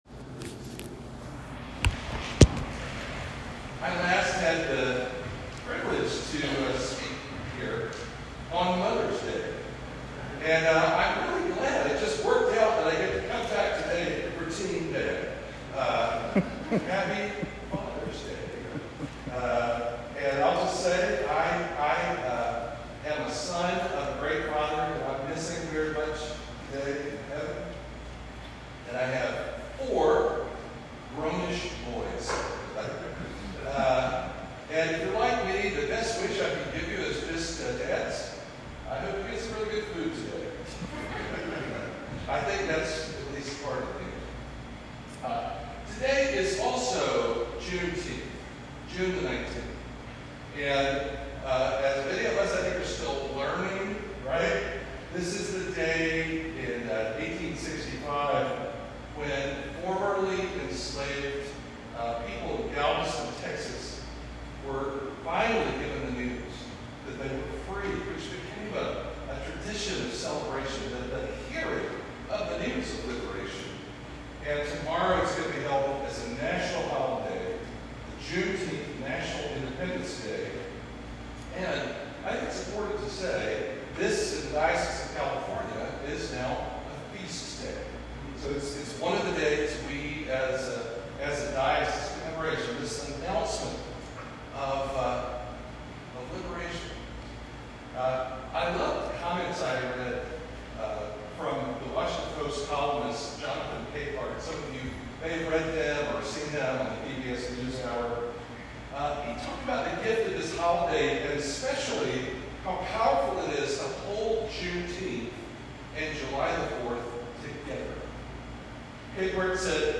Sermon from June 21, 2022